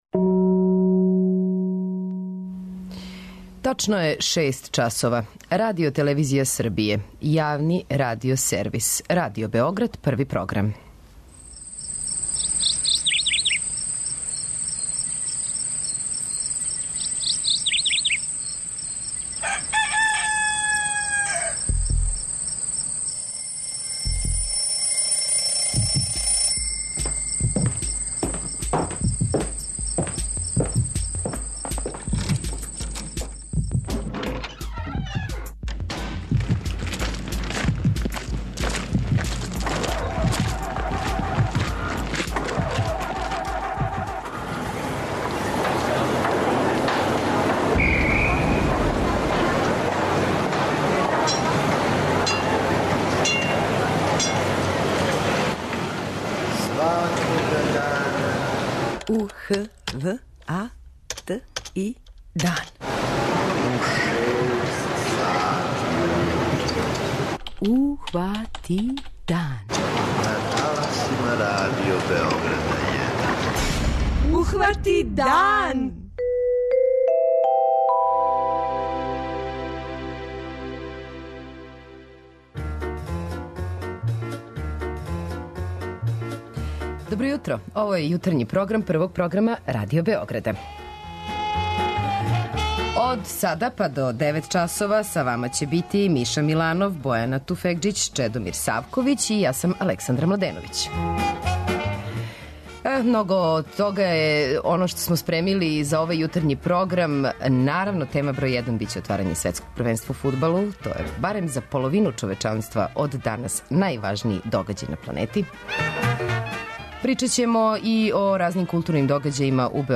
преузми : 85.80 MB Ухвати дан Autor: Група аутора Јутарњи програм Радио Београда 1!